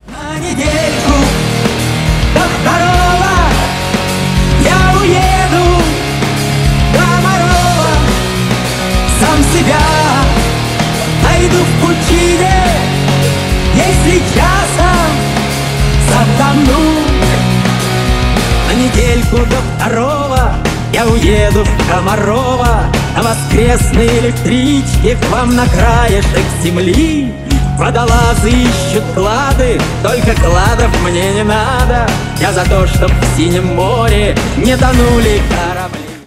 Ремикс # Рок Металл